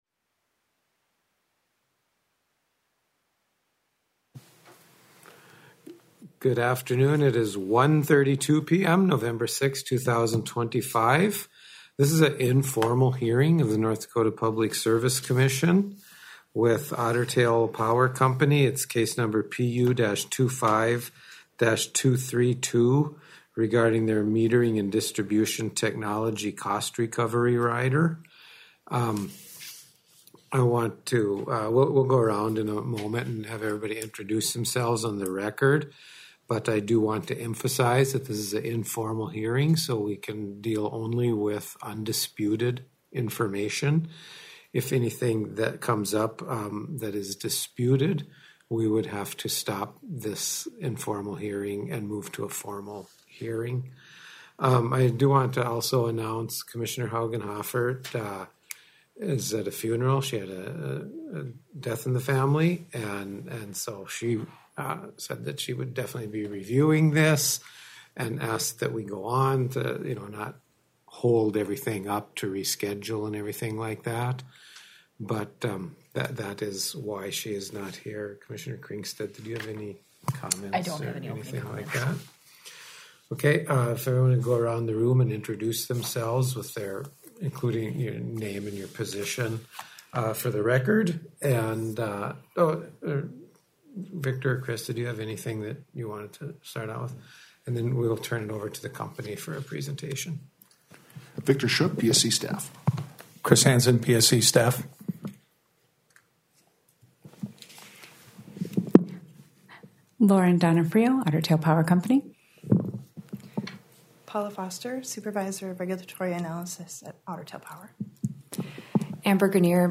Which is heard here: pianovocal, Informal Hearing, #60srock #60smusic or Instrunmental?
Informal Hearing